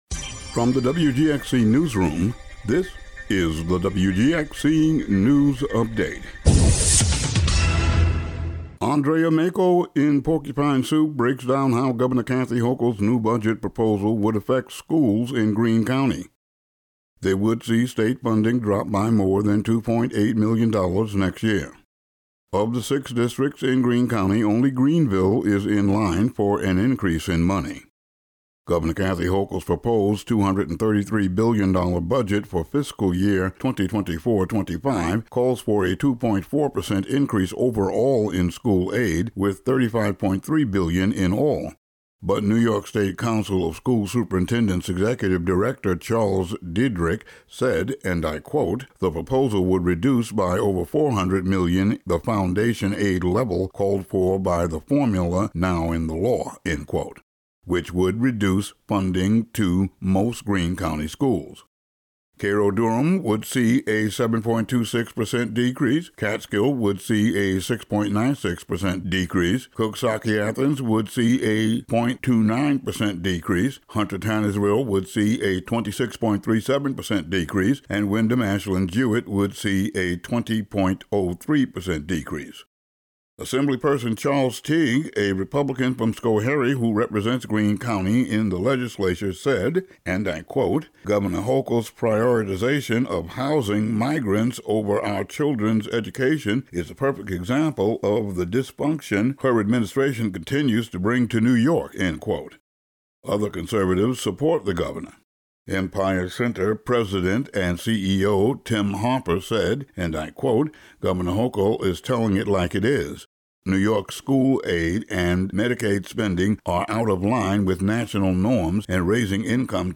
Today's daily local audio news update.